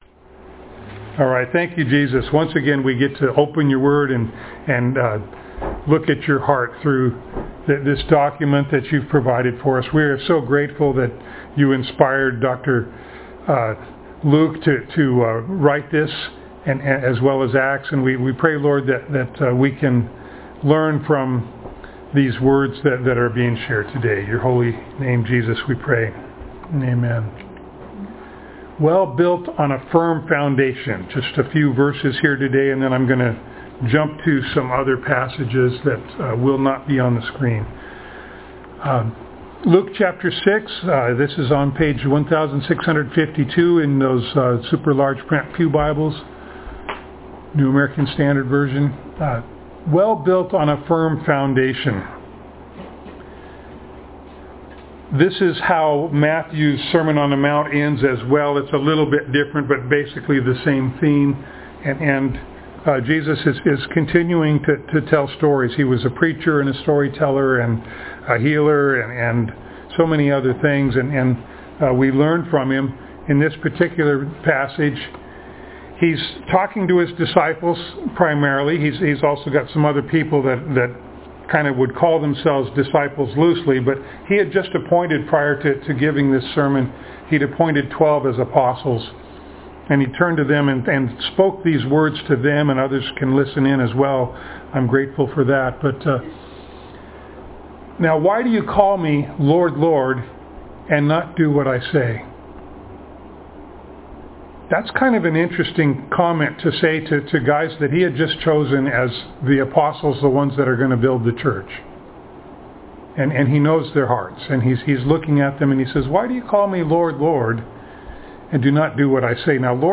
Luke Passage: Luke 6:46-49, John 13:33-14:27, 1 John 2:1-6, James 1:19-27 Service Type: Sunday Morning